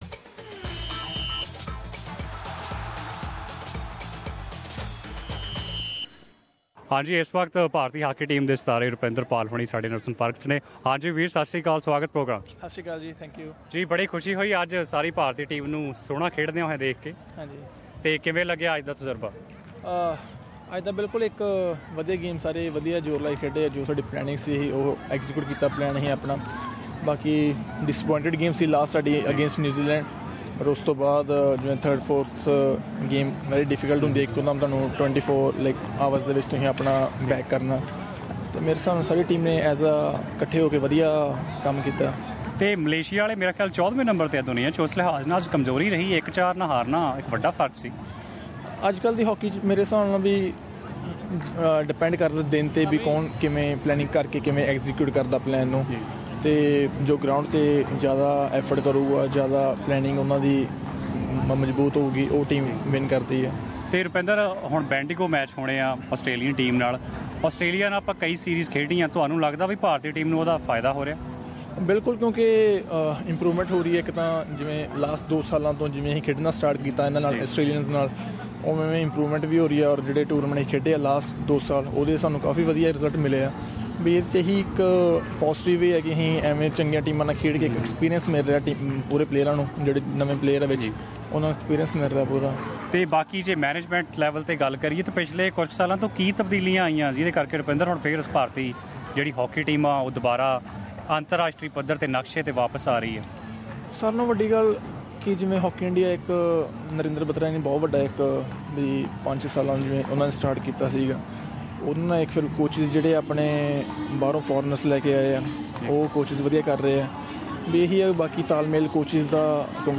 straight from the field of International Festival of Hockey at Melbourne.